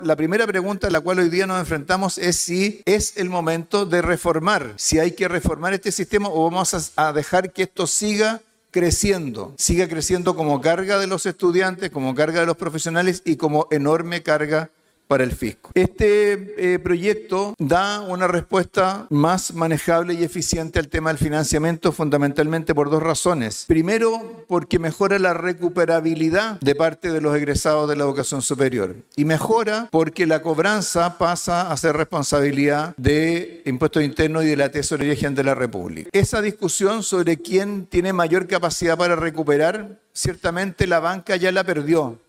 Durante el debate en sala, el ministro de Hacienda, Mario Marcel, destacó que el CAE no cumplió con las expectativas y se convirtió en una pesada carga fiscal y social.